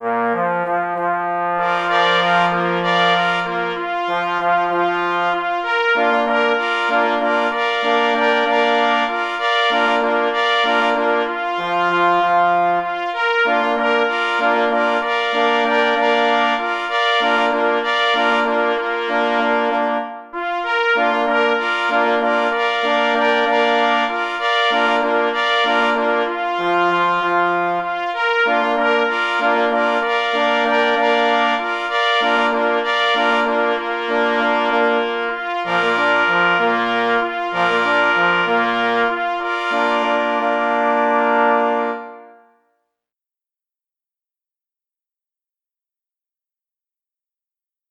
Opis zasobu: walc na 3 plesy i 2 parforsy, oparty na śląskich motywach ludowych Tytuł utworu: Walczyk woszczycki Kompozytor: […]
K. Anbild – Walczyk woszczycki – 3 plesy i 2 parforsy | PDF